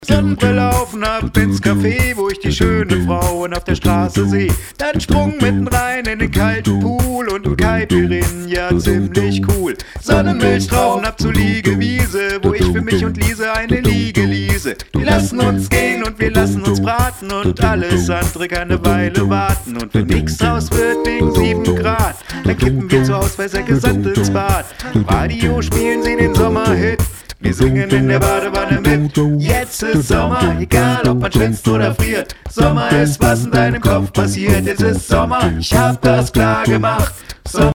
Ich bastel gerade an nem Lied rum wo alle sagen werden: Das gibts doch schon! Deswegen würde ich gerne die Bass-Stimme als Text deklarieren.